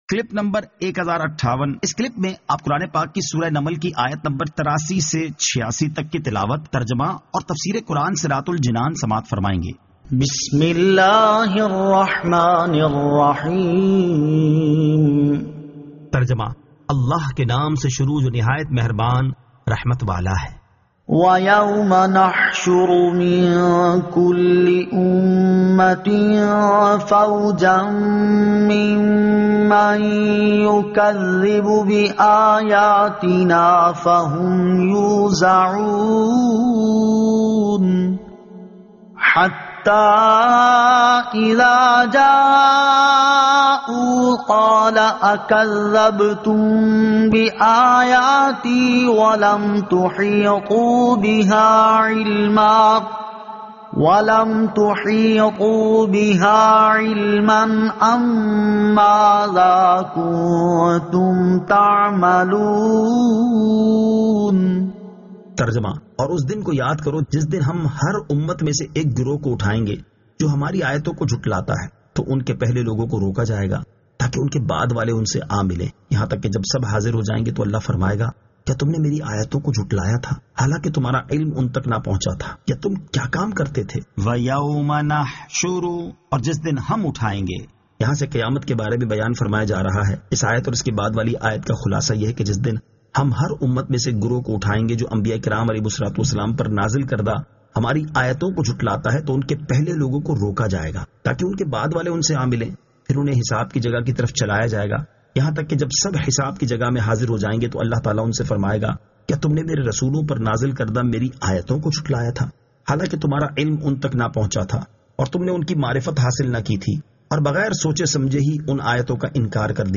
Surah An-Naml 83 To 86 Tilawat , Tarjama , Tafseer